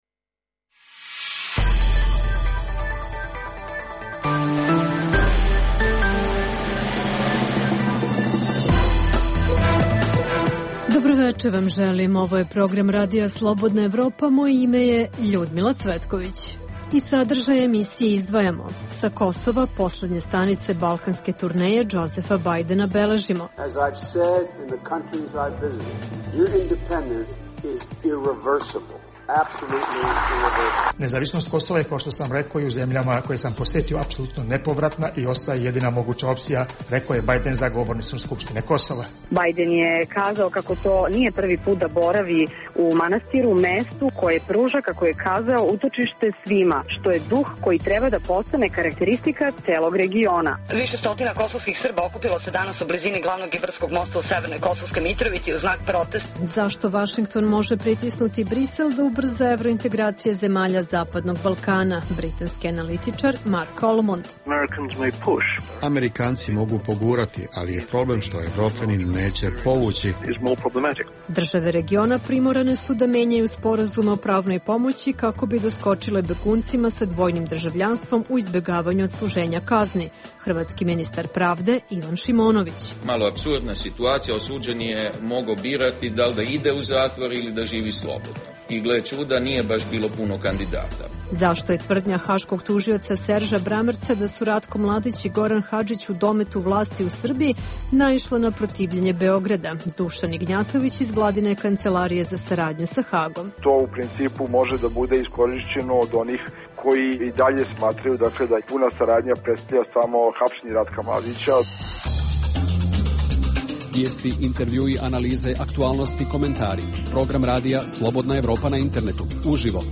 Centralna tema emisije je poseta američkog potpredsednika Džozefa Bajdena regionu. Čućete naše reportere iz Prištine, Dečana i Mitrovice; istražujemo kada ce BiH i Hrvatska potpisati sporazum koji će okončati izbegavanje odsluženja sudskih kazni na osnovu dvojnog državljanstva, te da li se haški begunci nalaze u dosegu srpskih vlasti, kao što navodi haški tužilac.